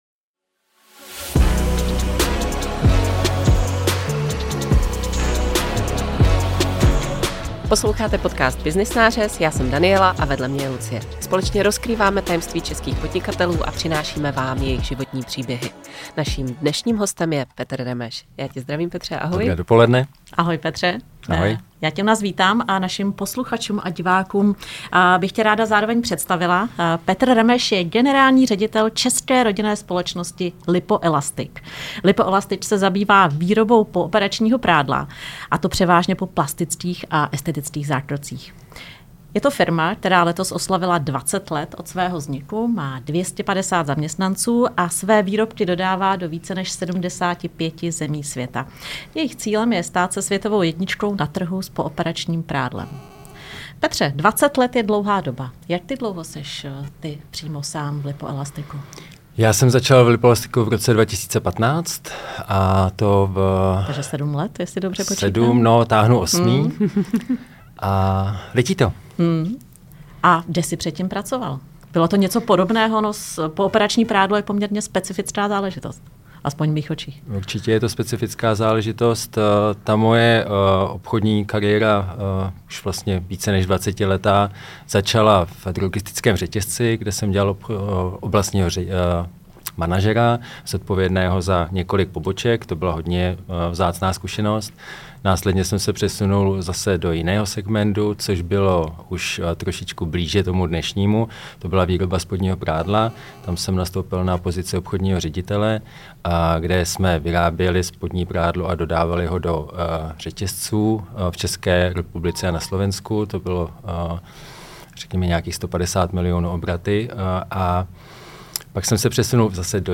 V rozhovoru s ním se dozvíte, jak funguje oblast plastické a estetické chirurgie, která pro LIPOELASTIC znamená hlavní zdroj zákazníků. Jaké jsou trendy, co jsou nejoblíbenější zákroky a jakou má pověst česká estetická chirurgie?